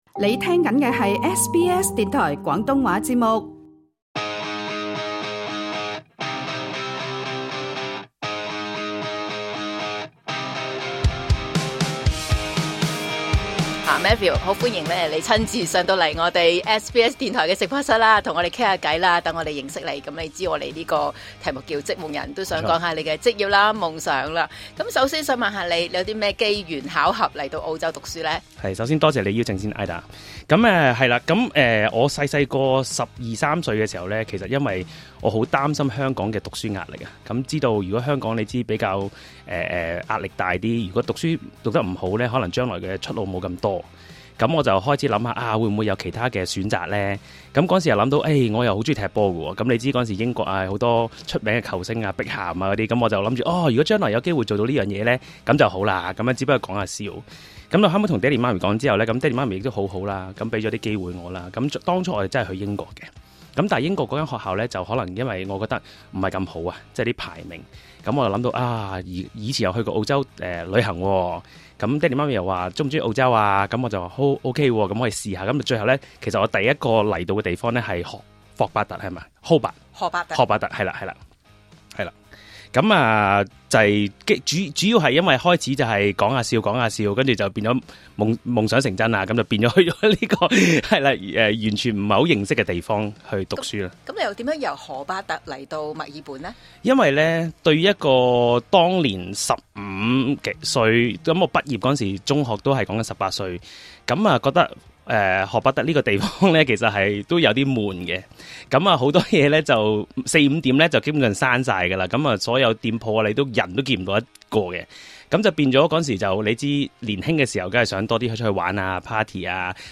訪問詳情，請收聽今集【職‧夢‧人】。